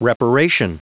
Prononciation du mot reparation en anglais (fichier audio)
Prononciation du mot : reparation